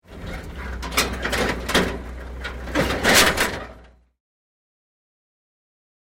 Шум тряски в кузове эвакуатора